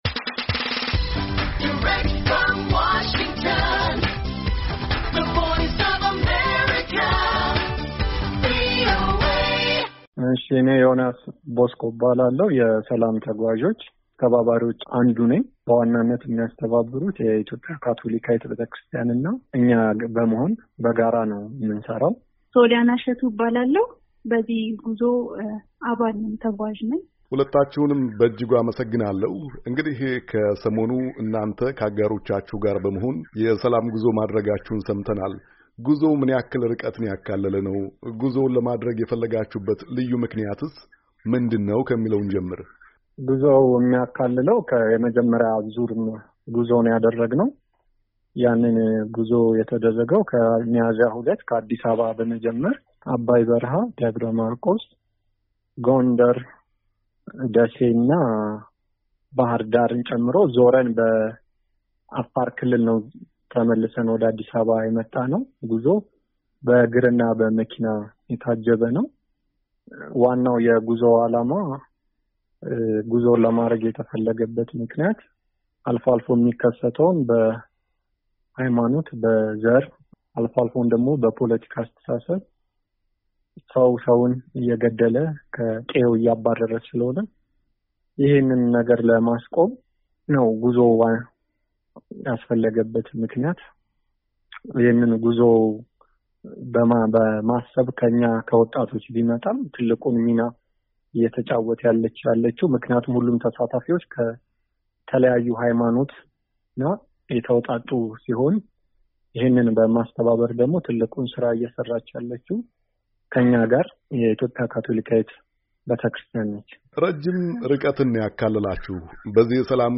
ዛሬ ለውይይት የጋበዝናቸው ሁለት ወጣቶች በመሰል እንቅስቃሴዎች ከሚጠቀሱት መካከል ናቸው።